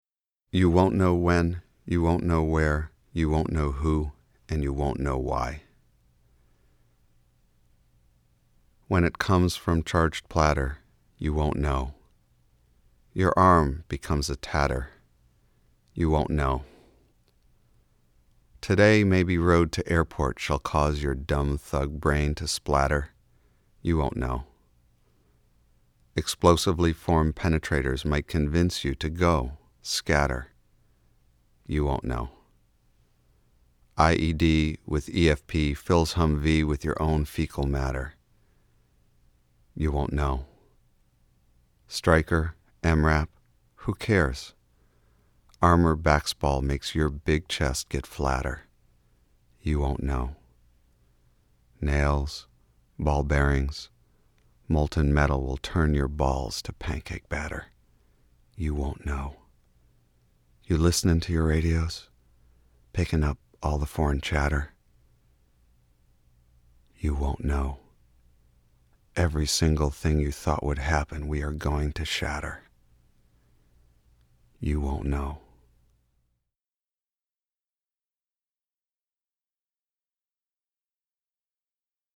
ghazals — lyric poems with a repeated rhyme, set to music